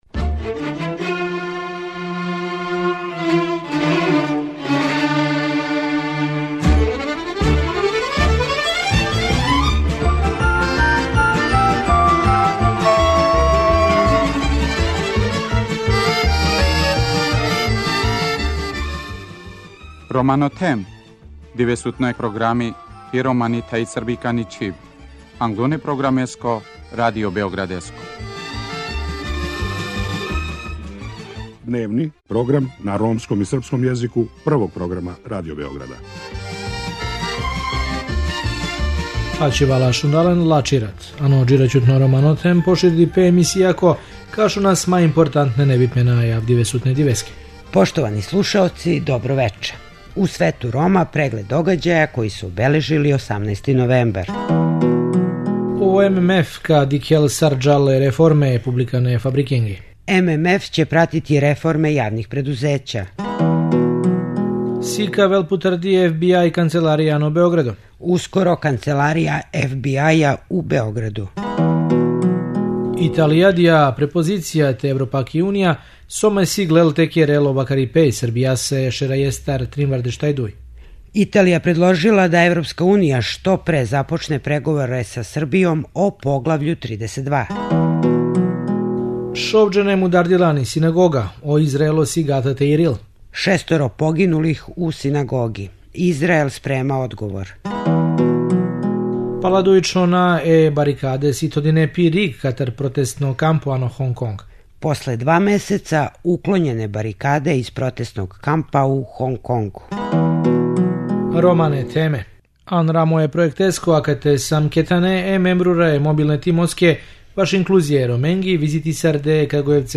Гост Света Рома је Вељко Кајтази, представник националних мањина у хрватском Сабору, говориће о свом раду и резултатима које је до сада постигао.